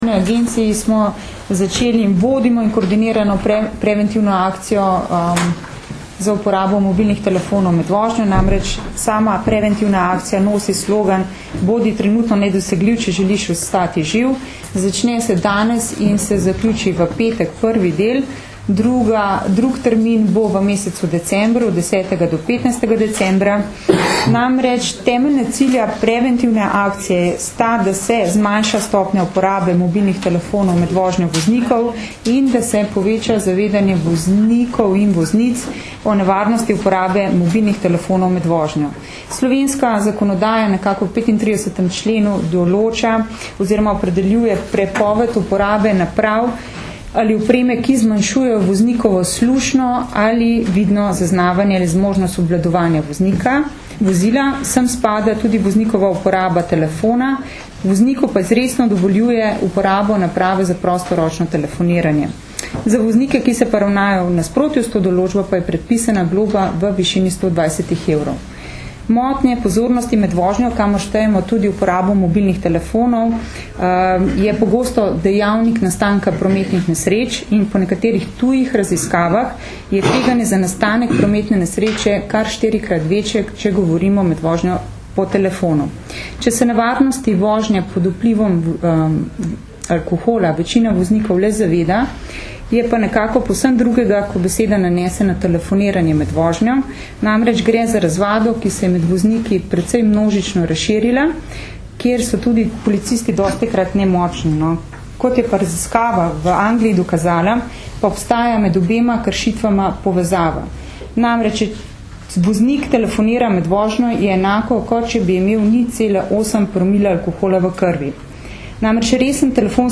Zvočni zapis izjave